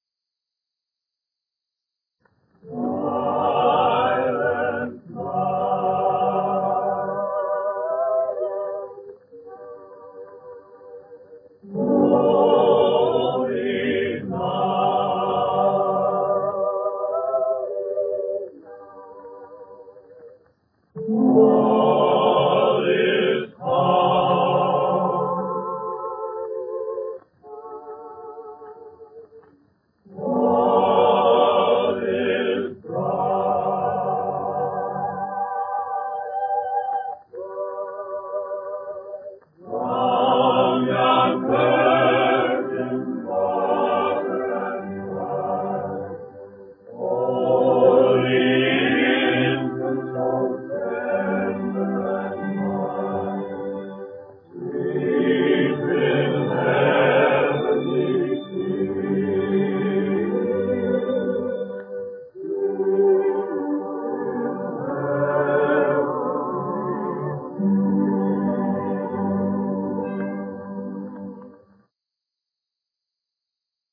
OTR Christmas Shows - Nelson Eddy and Choral Group, with Robert Armbruster and his Orchestra - Silent Night - 1946-07-xx 651 V-Disc B